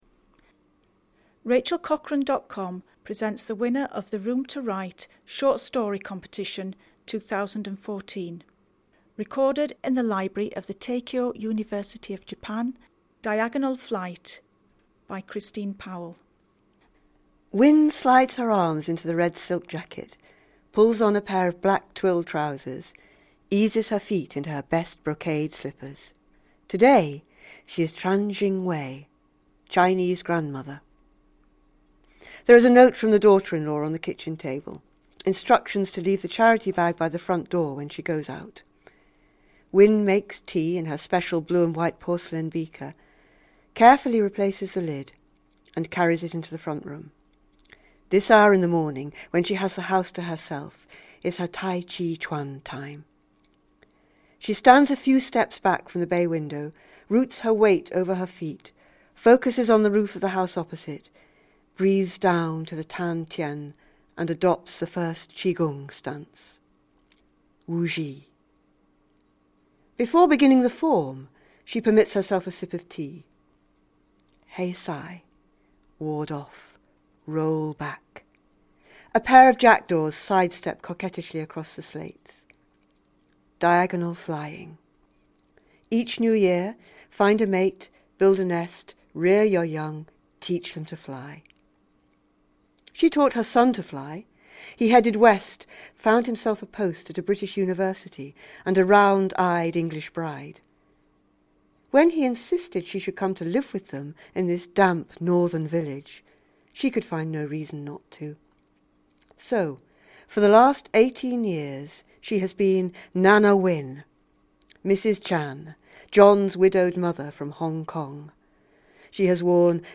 'Diagonal Flight' a short story